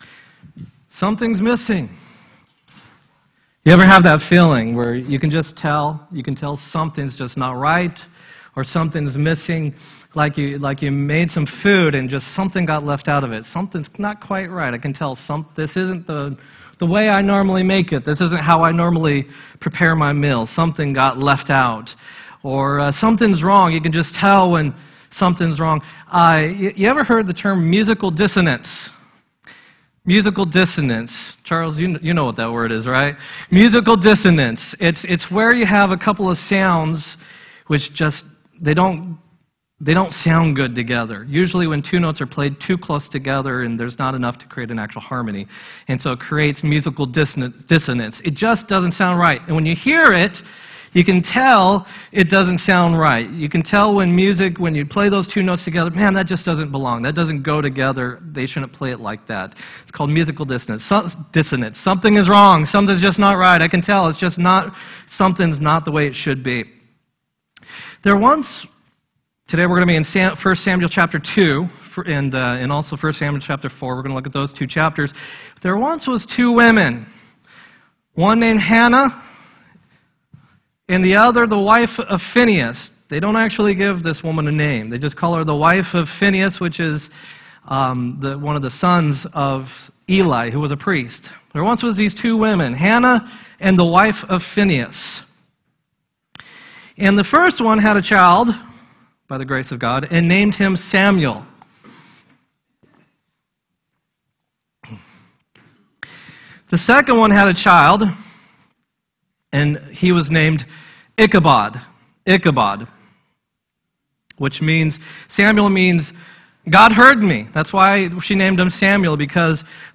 9-11-17 sermon
9-11-17-sermon.m4a